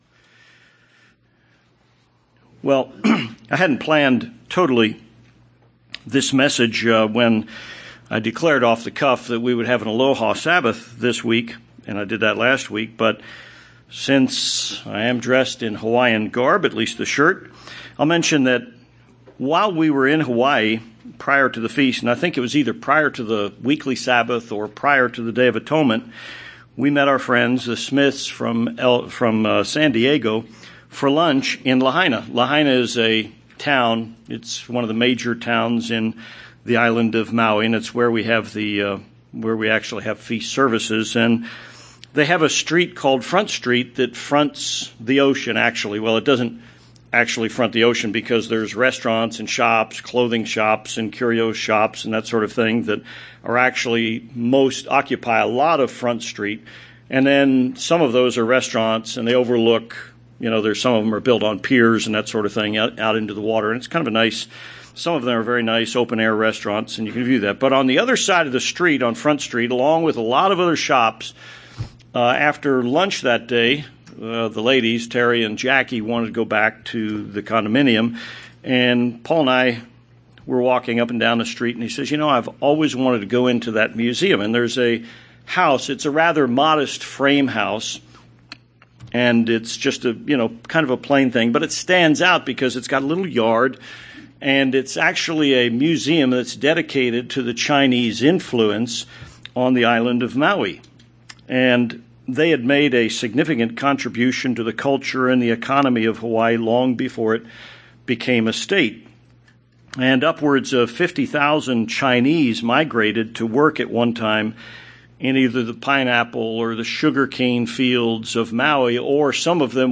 This sermon examines this question.